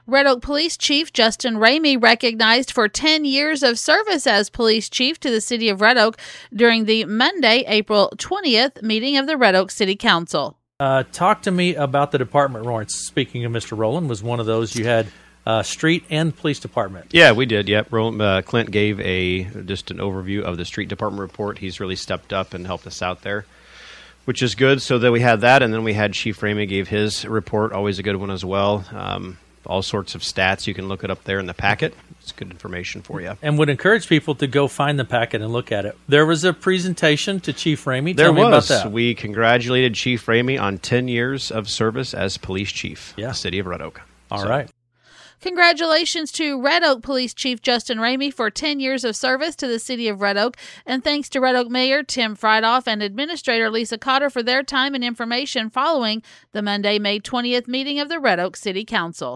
Red Oak Police Chief, Justin Rhamy recognized for 10 years of service as Police Chief at the Monday April 20th meeting of the Red Oak City Council.